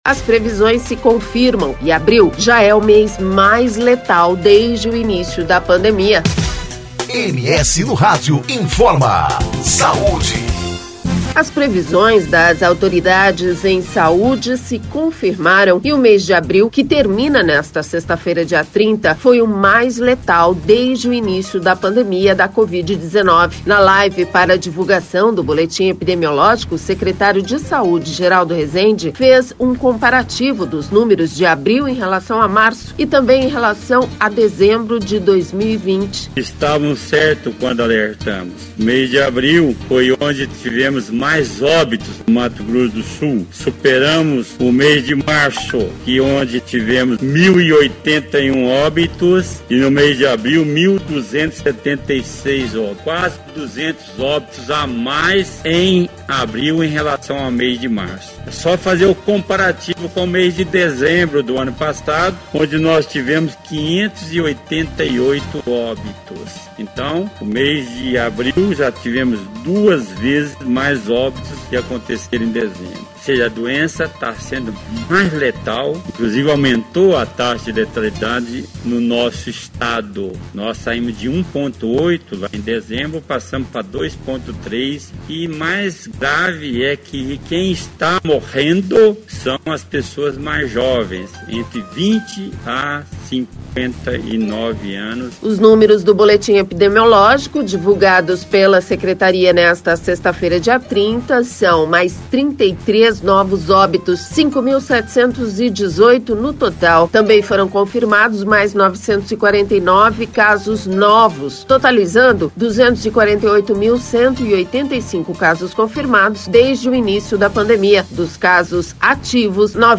Na live para divulgação do boletim epidemiológico, o Secretário de Saúde Geraldo Resende fez um comparativo dos números de abril em relação a março, e também em relação a dezembro de 2020.